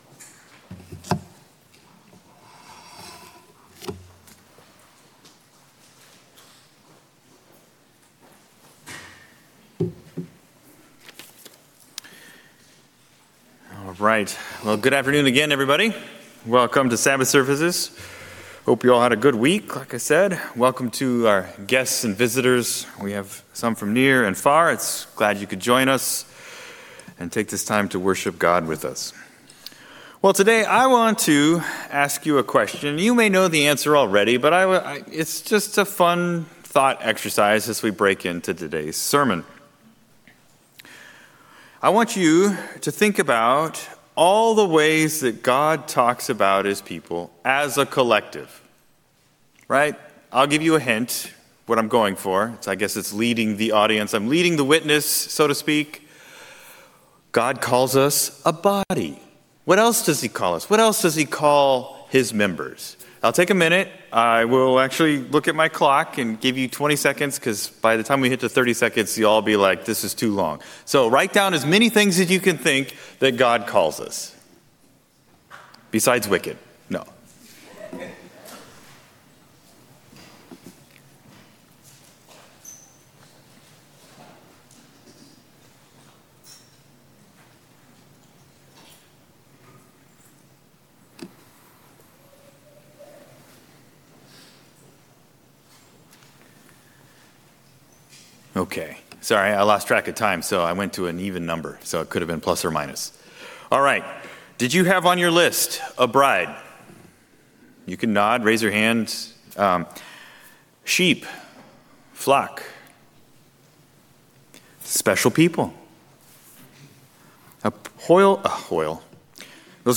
Given in Salem, OR